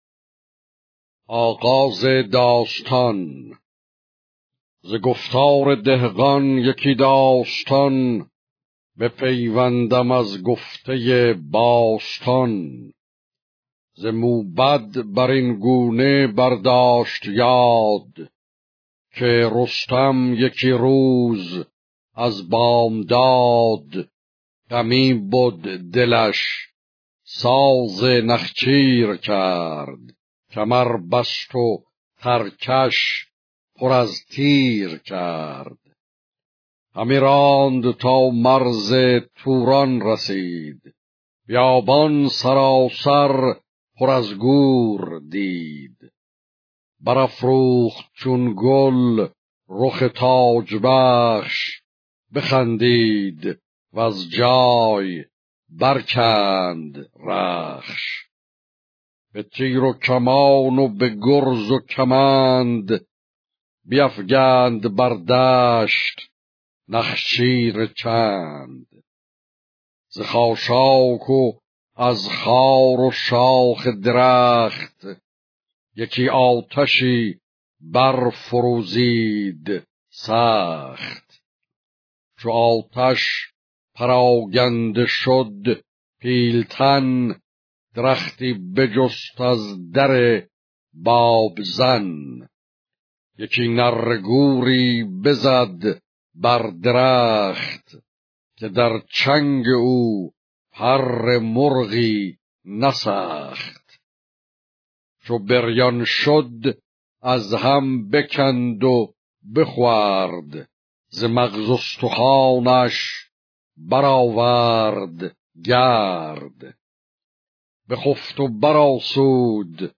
شاهنامه خوانی با صدای استاد سید جلال الدین کزازی